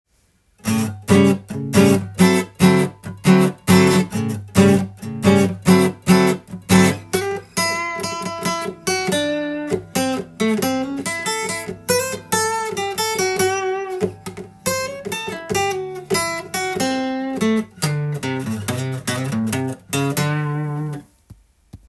カンタンブルージーコード
A7/D7
全ての音源で適当に弾いているだけですが
①は、ブルースでよく出てくるA7とD7のコードを１小節ずつ